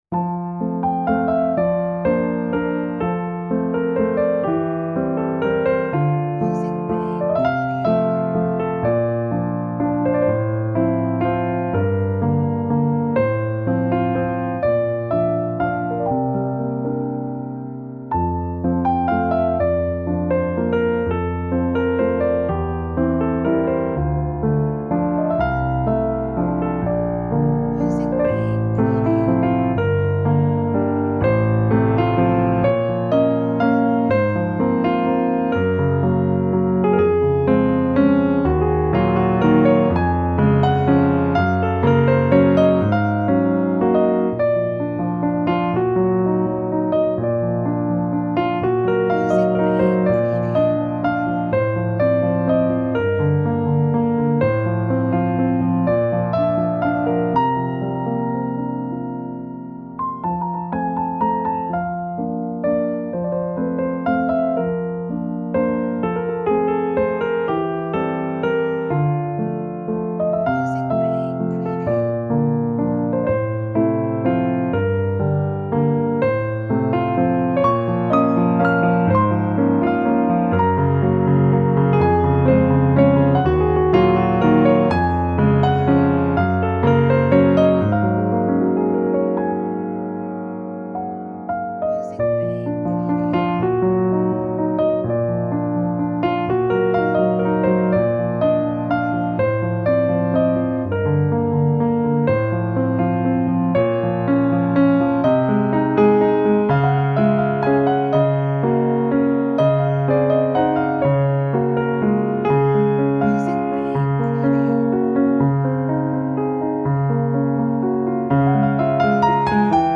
Cinematic music download